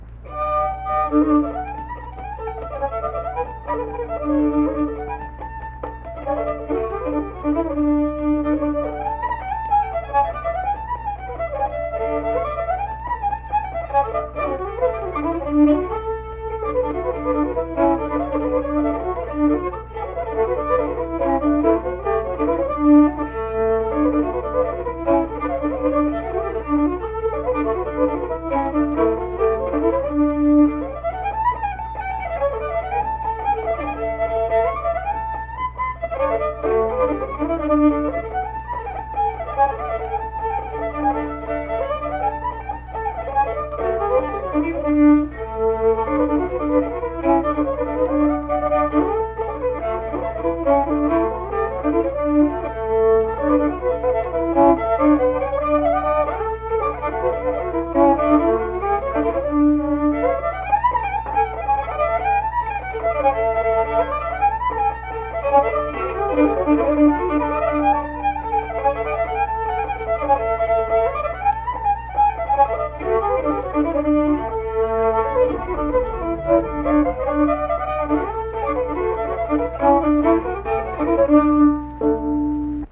Unaccompanied fiddle music
Instrumental Music
Fiddle
Kirk (W. Va.), Mingo County (W. Va.)